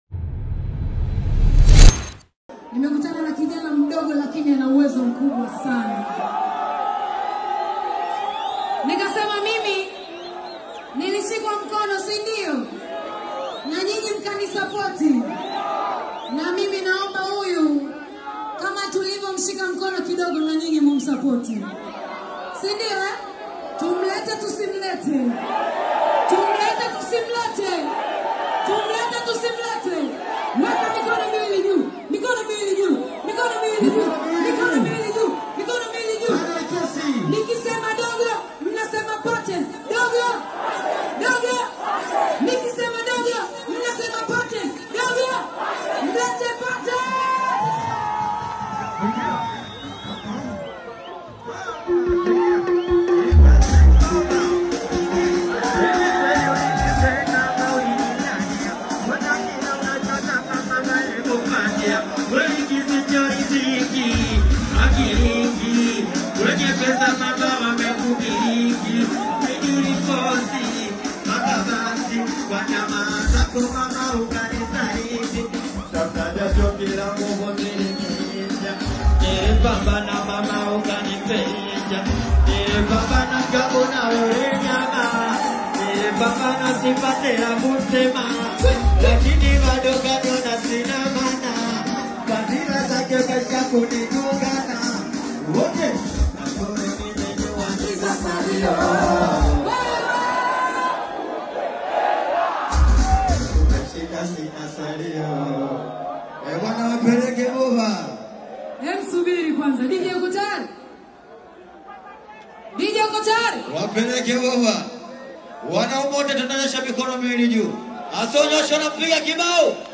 is a high-energy Singeli/Bongo Flava live performance
Genre: Singeli